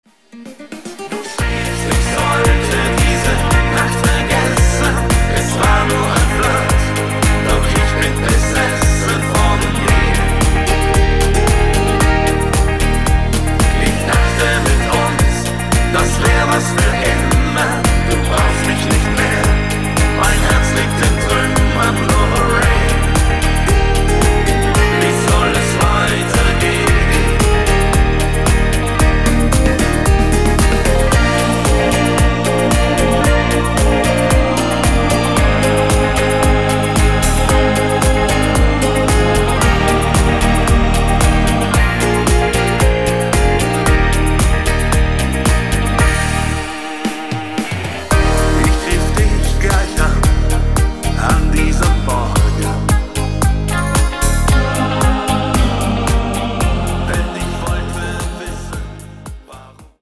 Rhythmus  8 Beat